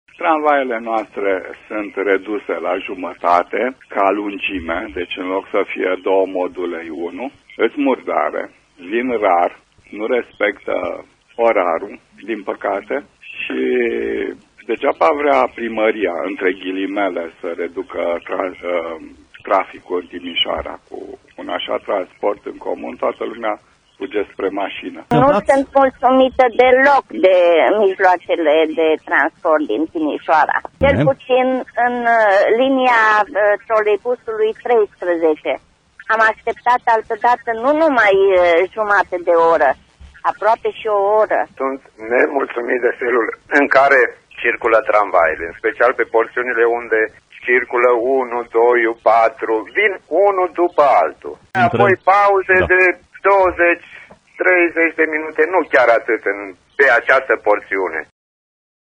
Timișorenii reclamă serviciile RATT, în direct, la Radio Timișoara.
voxuri-transport-Tm.mp3